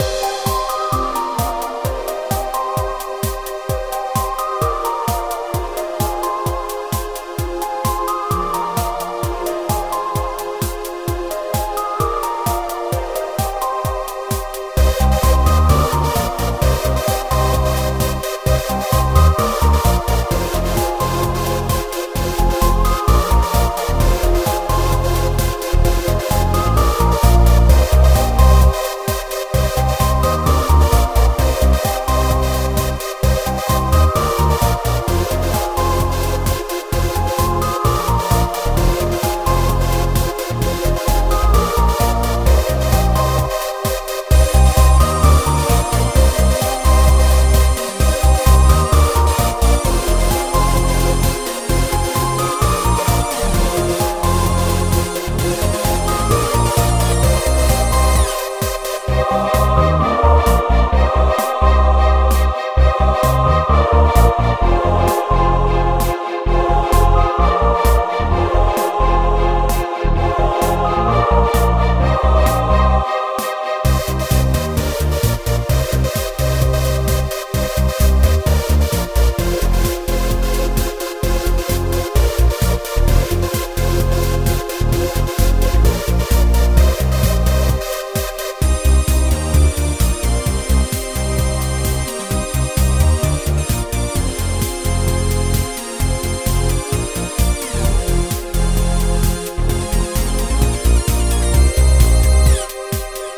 happy
cyber